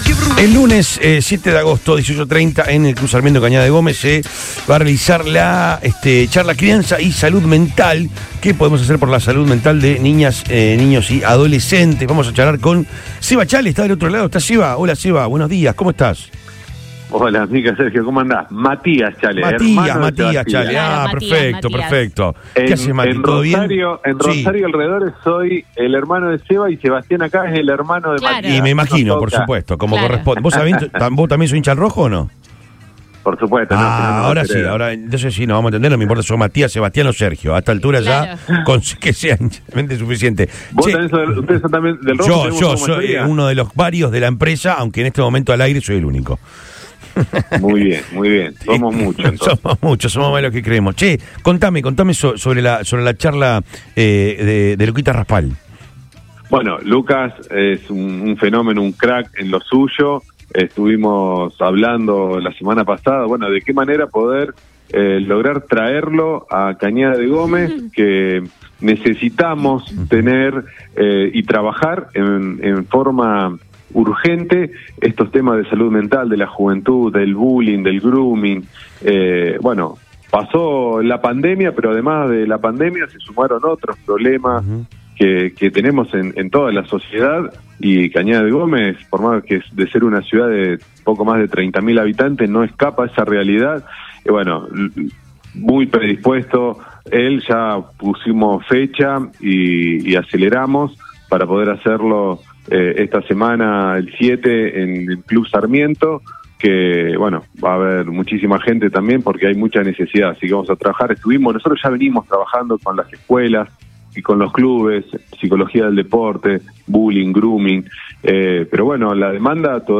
Este último funcionario, que también es precandidato a intendente de Cañada de Gómez, dialogó con Lo Mejor de Todo por Radio Boing, donde dio pormenores de la cita.